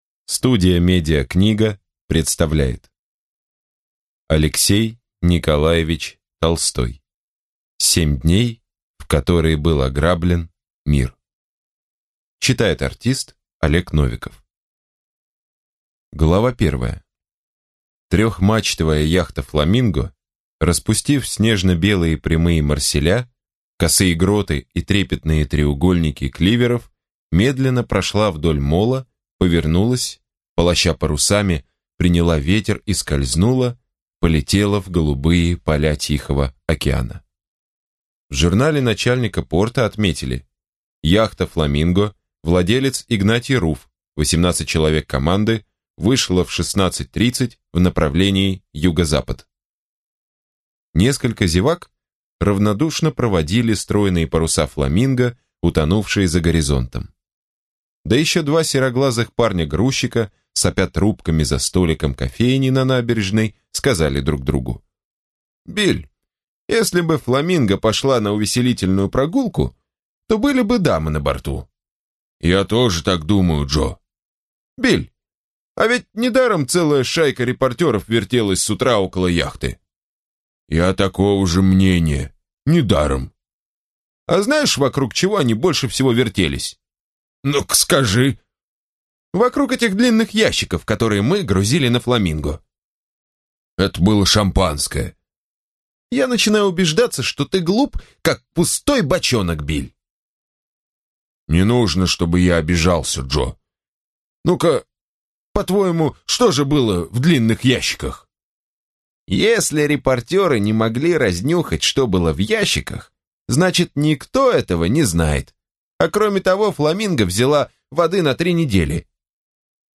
Аудиокнига Семь дней, в которые был ограблен мир. Союз Пяти | Библиотека аудиокниг